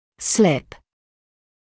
[slɪp][слип]скользить, соскальзывать